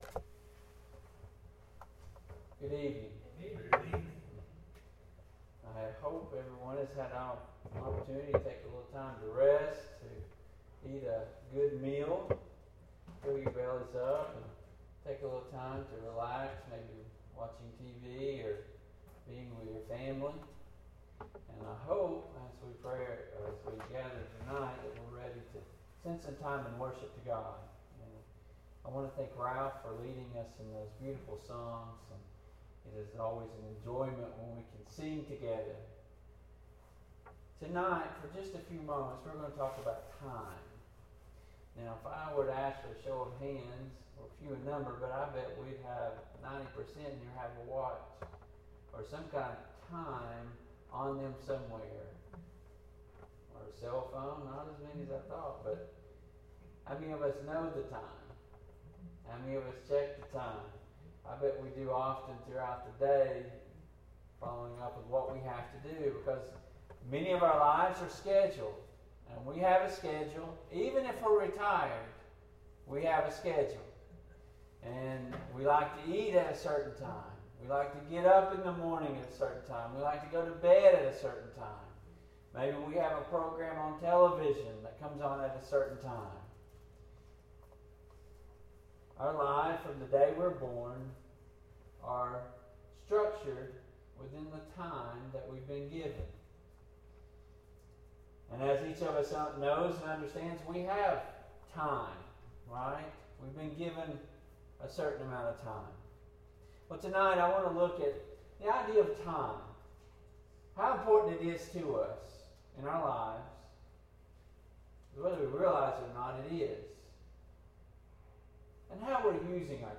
Passage: Ecclesiastes 3:1-8; Matthew 6:19-21; I Timothy 6:6-10; Acts 8:9-25; I Timothy 6:11-12; II Corinthians 4:16-18 Service Type: PM Worship